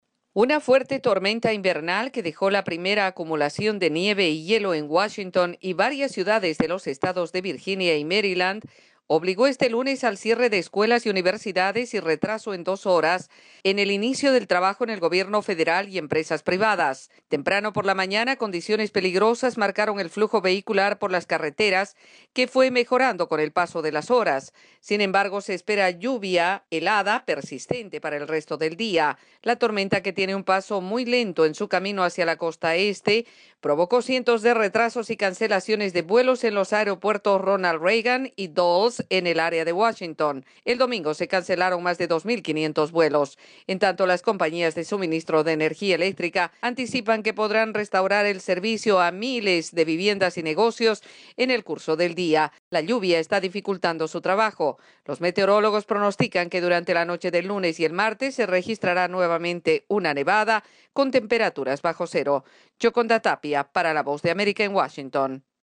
Informe sobre el clima